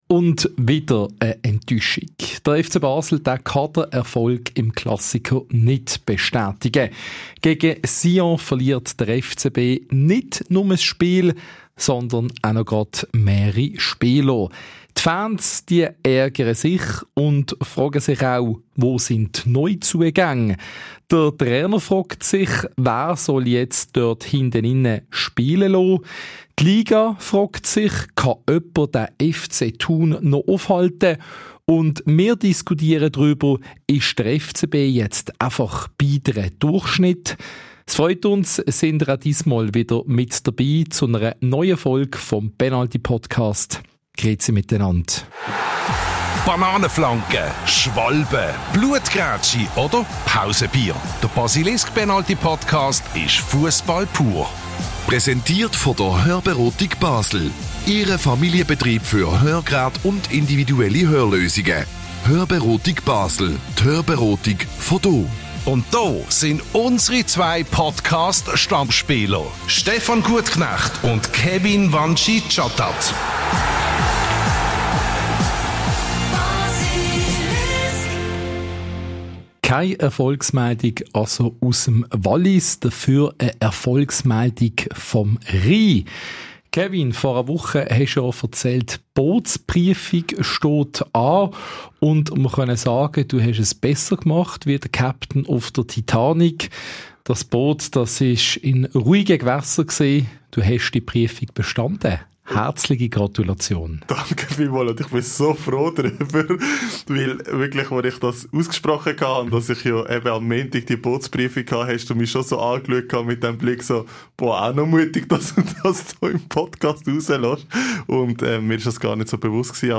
Unser Podcast-Duo spricht über die möglichen Konstellationen und beurteilt die Situation von Jungspund Akahomen.